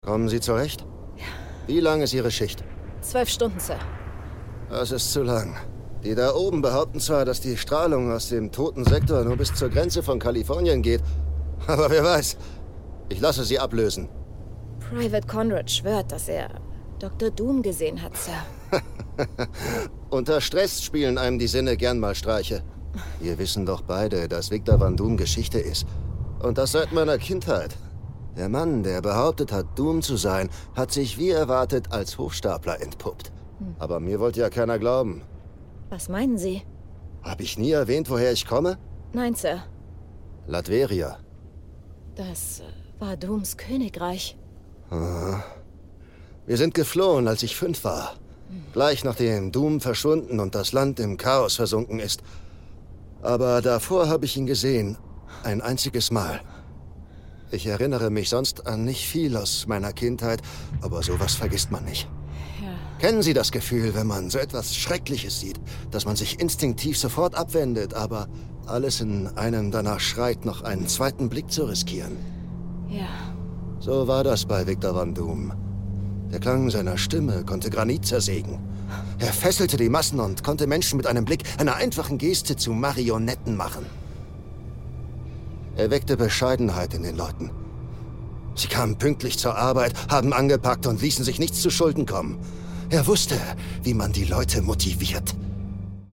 markant, dunkel, sonor, souverän, sehr variabel
Mittel minus (25-45)
Audio Drama (Hörspiel)